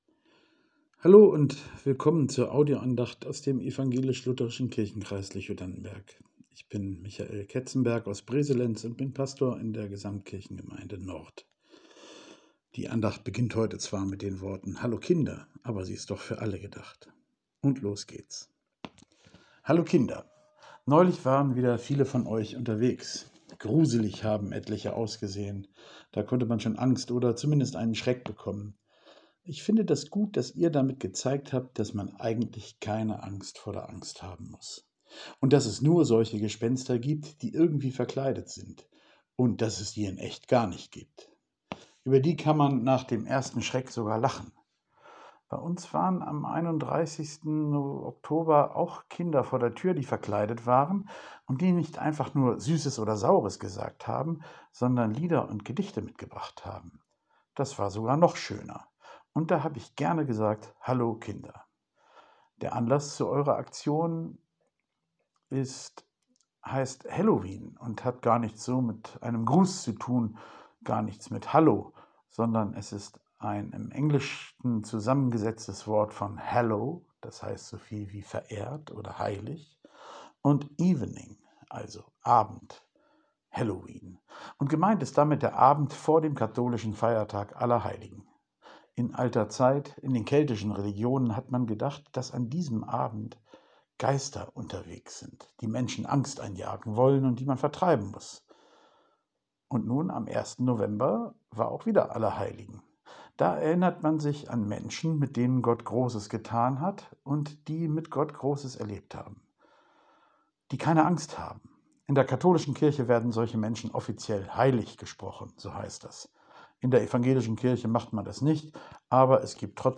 Telefon-Andacht